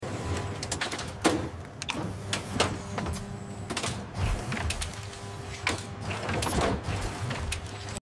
Chickens turn in the Automatic sound effects free download
Chickens turn in the Automatic Butcher machine